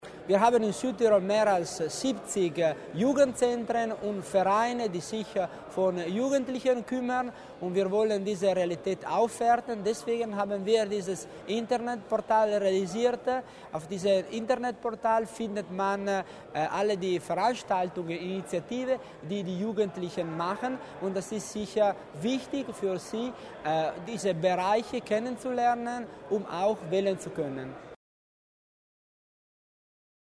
Landesrat Tommasini zur Bedeutung der neuen Projekte